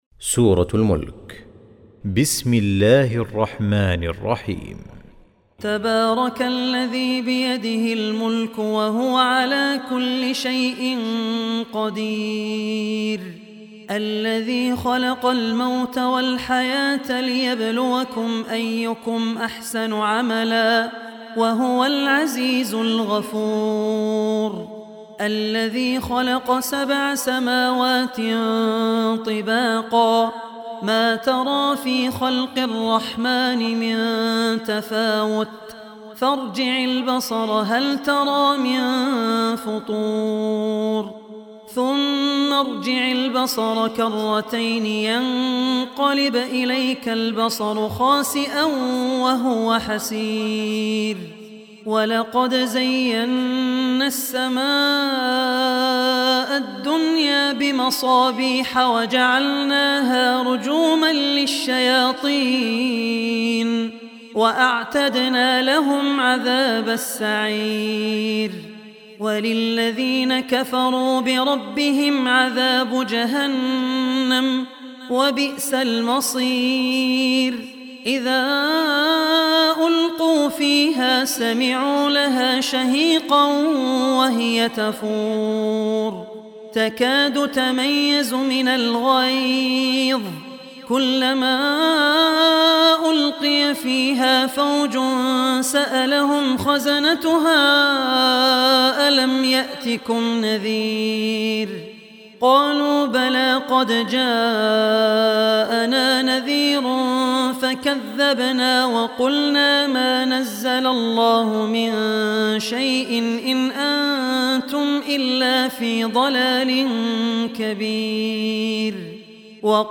شبكة المعرفة الإسلامية | القران | سورة الملك |عبد الرحمن العوسي